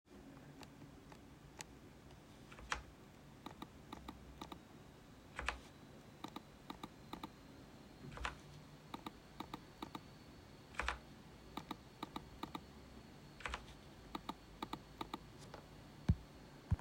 Das Klick-Gefühl kommt dabei dem einer aktuellen MX Master 4 oder einem MacBook-Touchpad gleich: Es ist dumpf, nicht harsch klickend – und klingt auch so.
Die nachfolgende Audioaufnahme verdeutlicht den Klangverlauf vom deaktivierten „Haptik-Klick“ bis zur höchsten Stufe 5. Es sind jeweils drei Klicks der Primärtaste zu hören, dann ein Druck auf den Cursor einer mechanischen Tastatur um die Feedback-Stufe ausgehend von „0“ zu erhöhen. Die X2 Superstrike wird nicht laut und kann auf niedriger Stufe sehr leise sein.
Klick-Geräusch der Logitech G Pro X2 Superstrike von „kein Klick“ (Stufe 0) bist Stufe 5